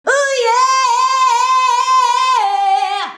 UYEAHYEAH.wav